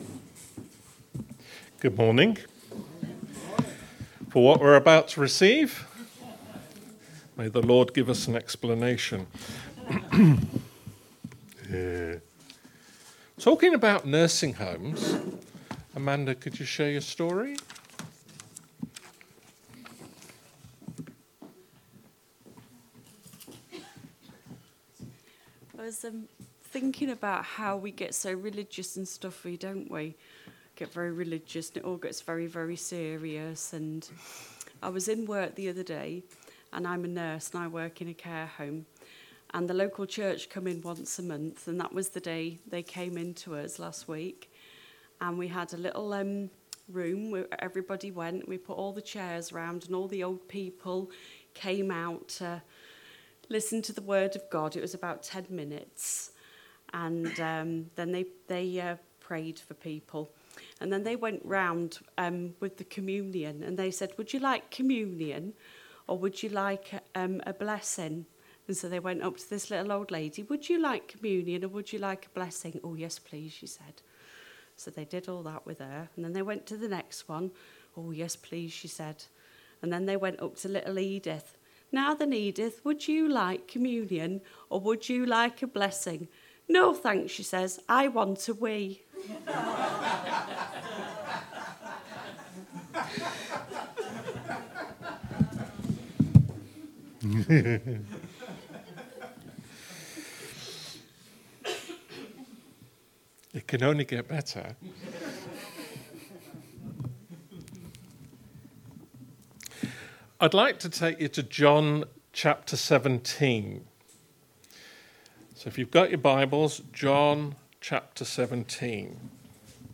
message from John 17